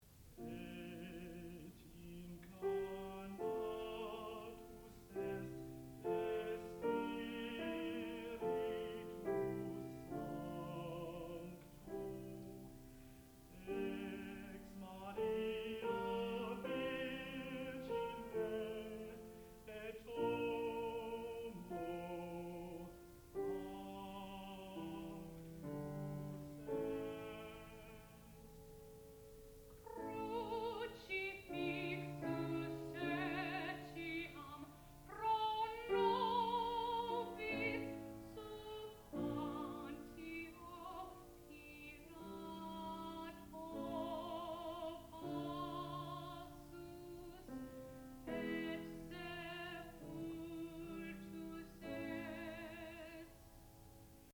sound recording-musical
classical music
piano
tenor